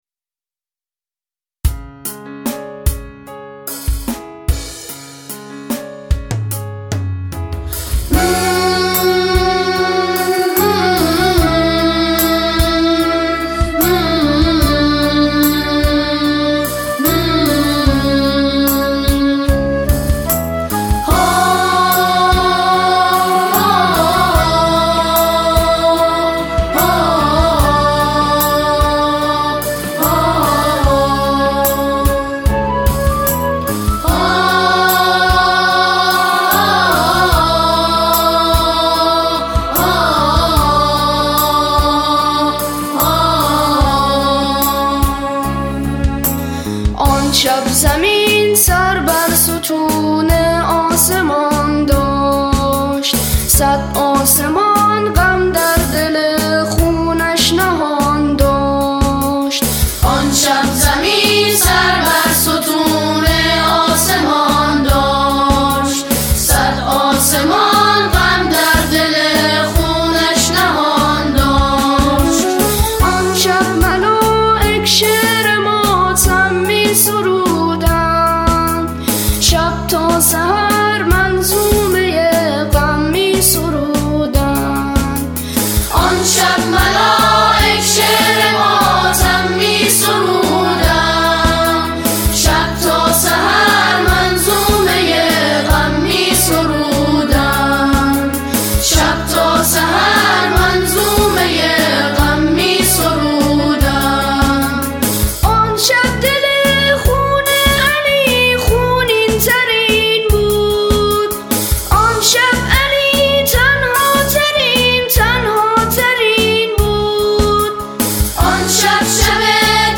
- بی کلام